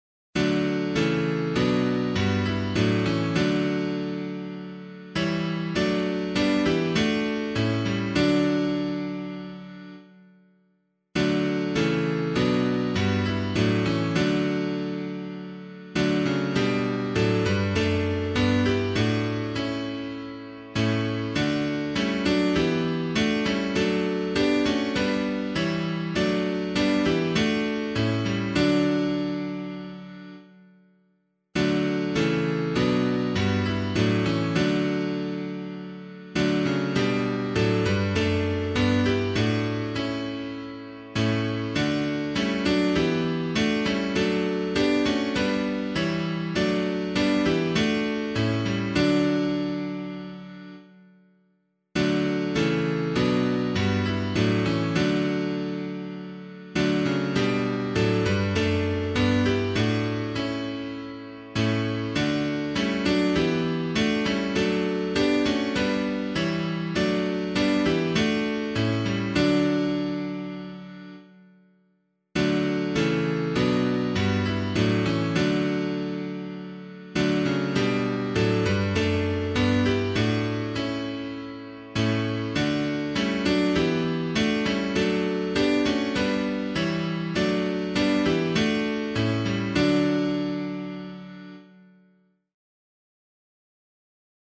piano
Breathe on Me Breath of God [Hatch - CARLISLE] - piano.mp3